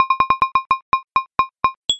scoreScroll.mp3